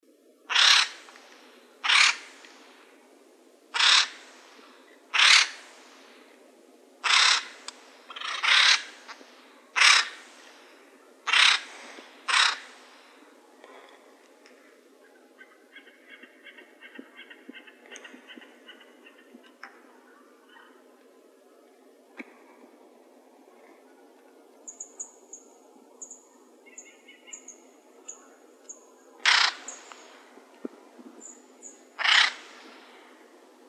woodpecker.wav